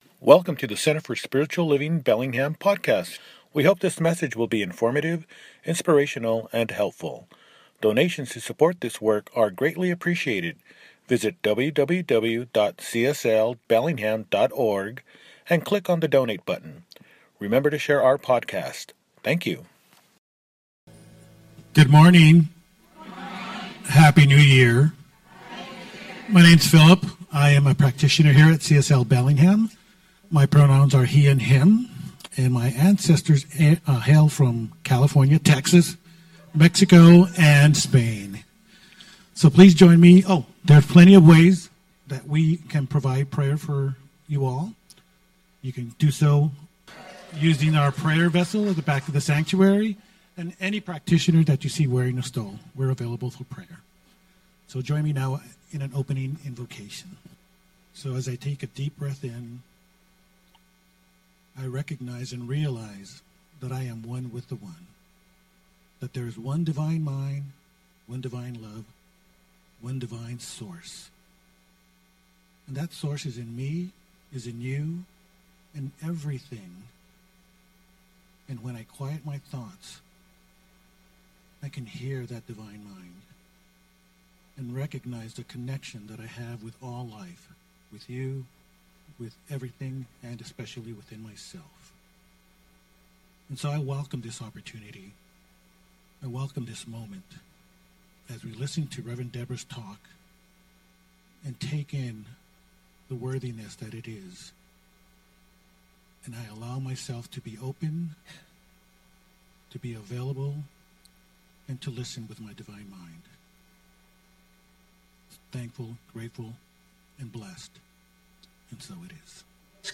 Divine Mind and the Cycles of Interconnection – Celebration Service